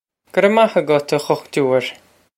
Pronunciation for how to say
Guh rev mah a-gut, ah ghukh-too-ir.
This is an approximate phonetic pronunciation of the phrase.